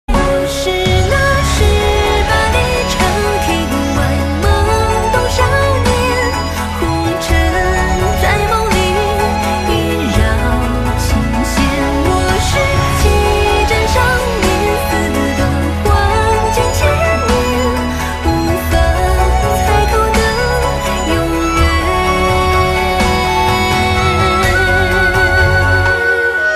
M4R铃声, MP3铃声, 华语歌曲 24 首发日期：2018-05-15 07:32 星期二